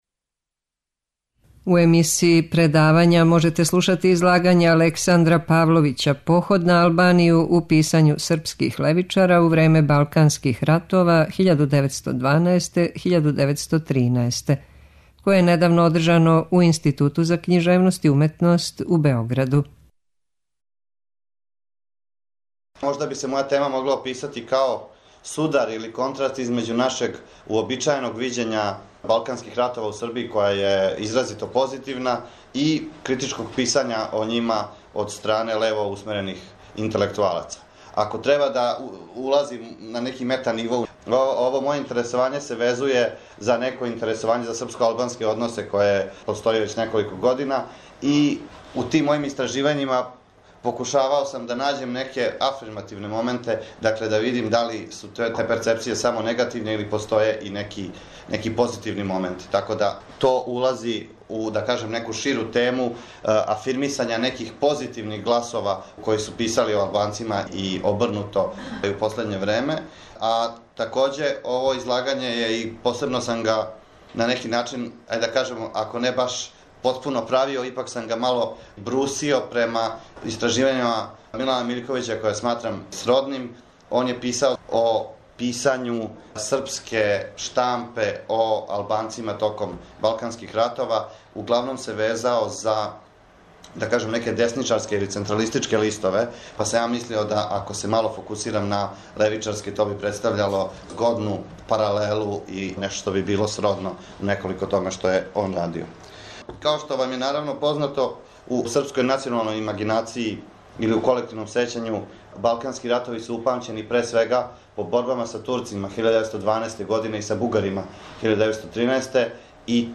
Предавања
преузми : 15.22 MB Радијска предавања, Дијалози Autor: Трећи програм Из Студија 6 директно преносимо јавна радијска предавања.